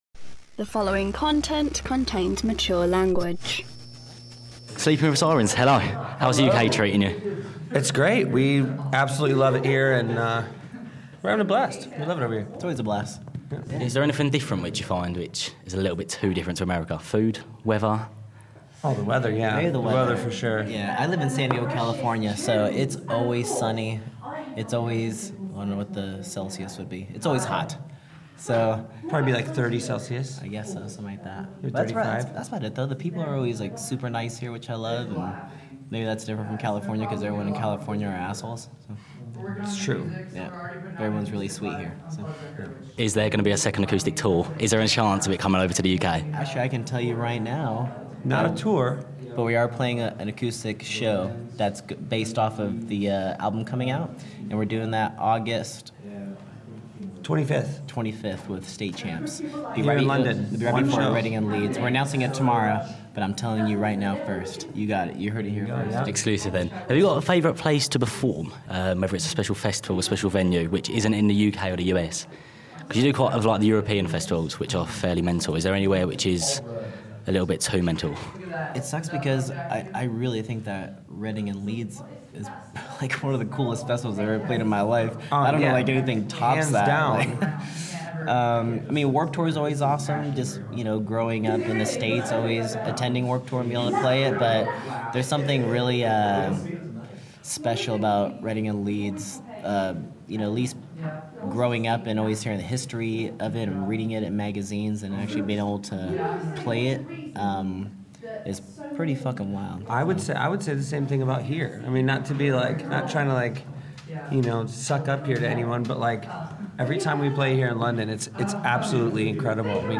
This interview contains mature language.